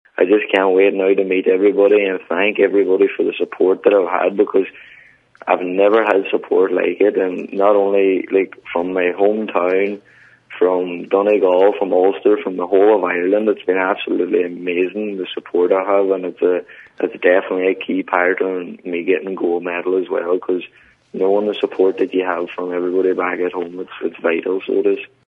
Speaking to Highland Radio this afternoon, Jason said he’s looking forward to tonight…